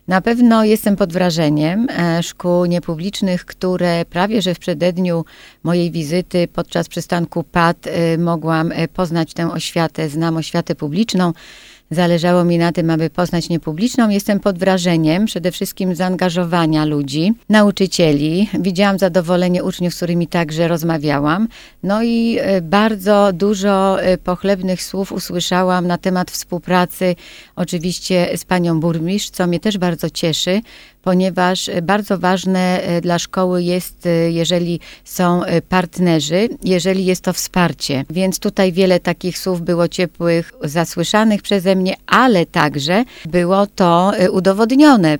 Mówiła Anna Łukaszewska, Kujawsko - Pomorska Kurator Oświaty.